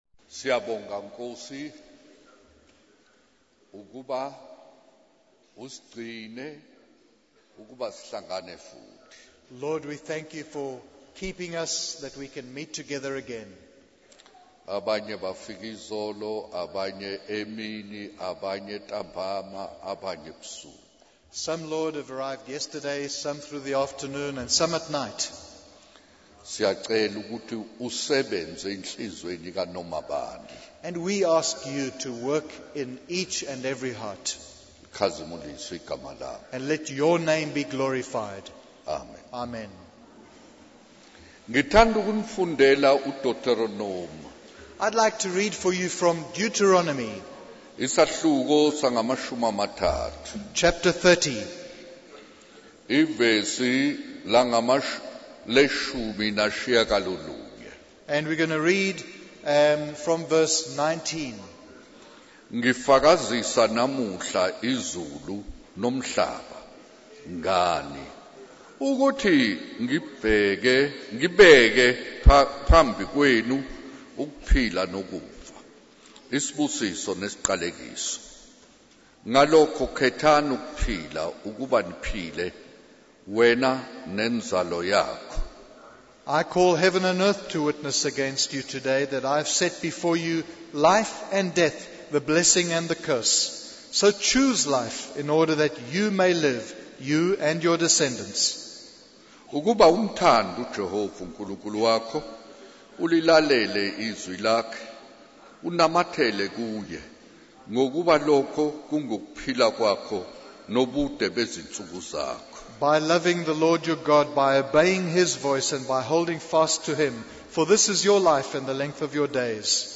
In this sermon, the speaker shares a story about a young man named Roger who is hitchhiking home after his military service.